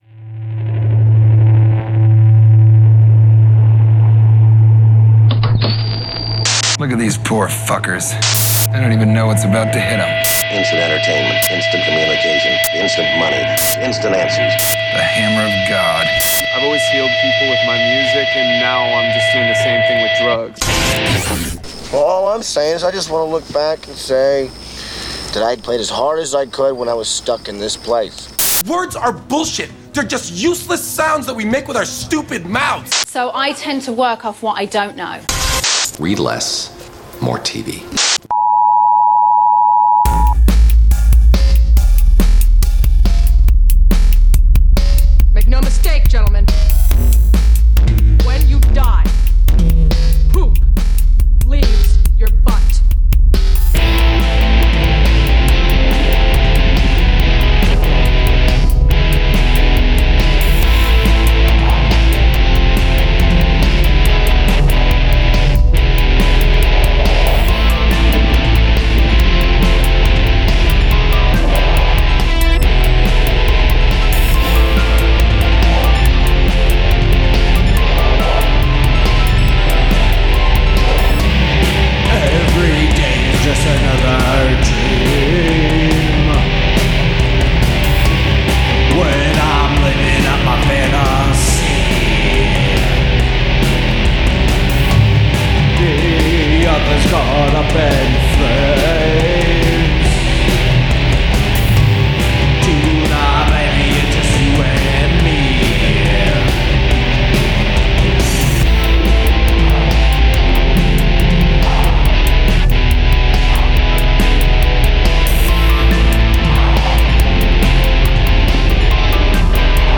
Re: Nu Grebo Music
Here is a recording of my recent live set (note; I was recording some practices leading up to a gig, trying to dial in some effects and transitions for the set.